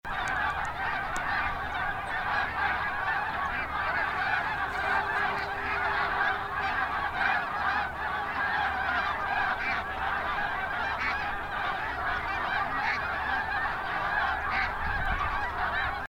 Snow Goose
Their call is a shrill, nasal la-uk or houck-houck.
snow-goose-call.mp3